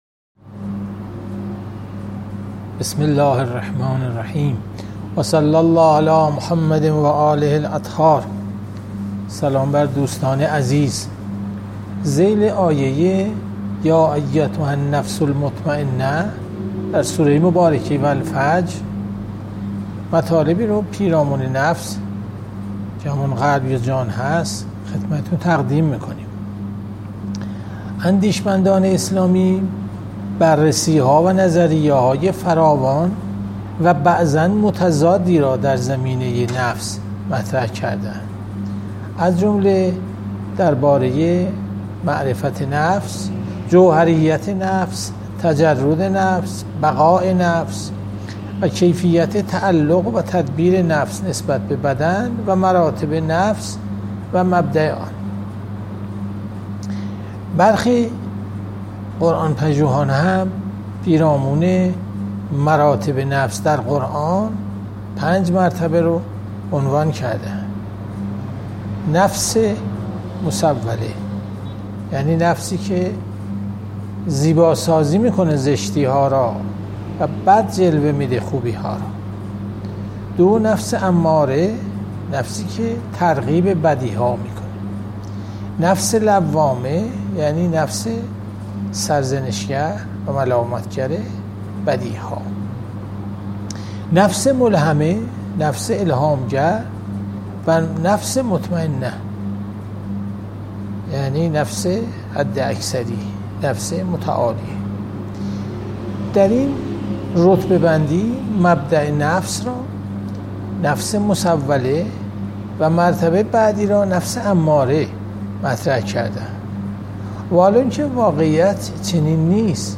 جلسه مجازی هفتگی قرآنی، سوره فجر، 22 خرداد 1401
تفسیر قرآن